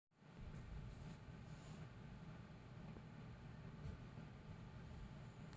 At the balanced setting when under load the cooler became slightly louder but it still managed to stay quiet enough that any sound from a game or video would easily drown out any noise.